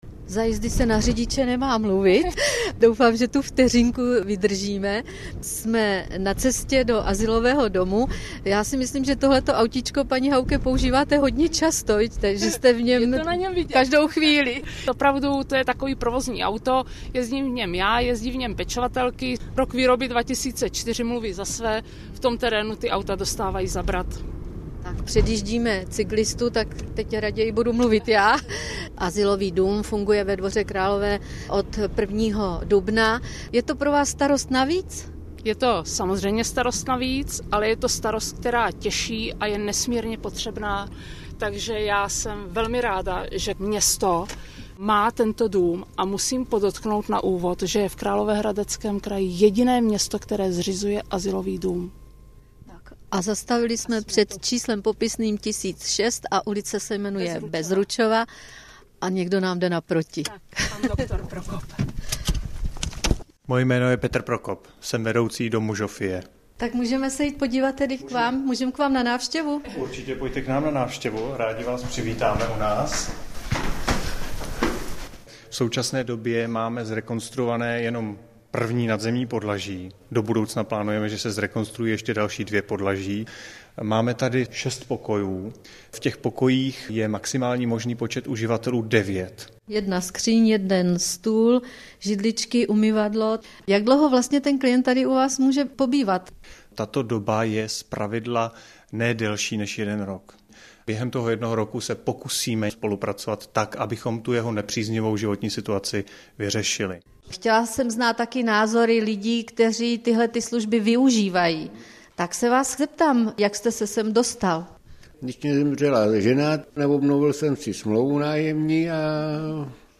První rozhovor najdete přímo zde...